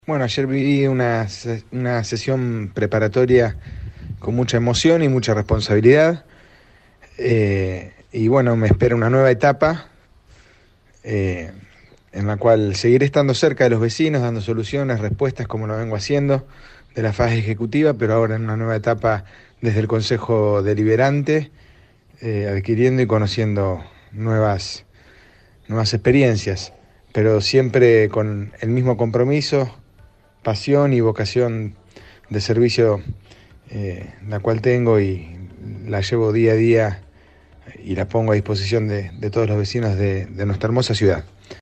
(incluye audios) Los flamantes ediles dejaron este viernes en la 91.5 su reflexión por lo vivido en el marco de la sesión preparatoria que tuvo lugar en la tarde del jueves en el salón «Dr. Oscar Alende» del HCD.